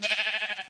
animalia_sheep_idle.ogg